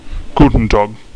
people
hello2.mp3